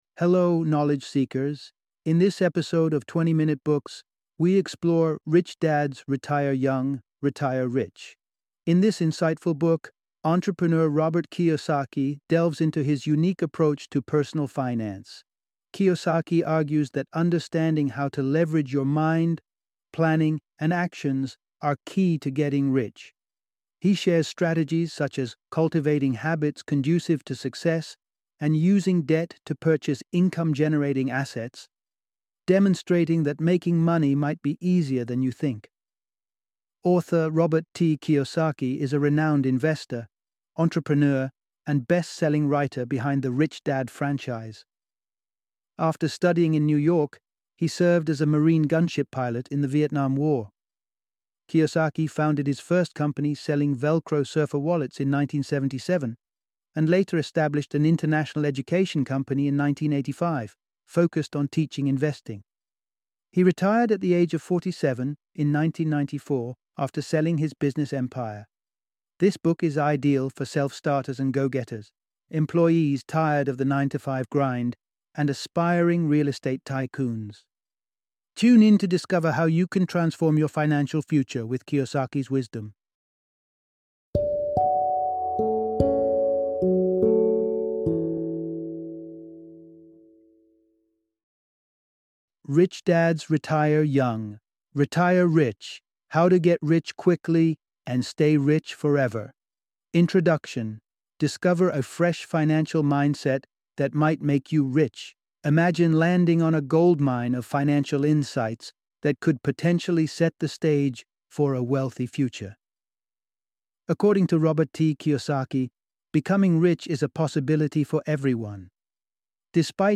Rich Dad’s Retire Young Retire Rich - Audiobook Summary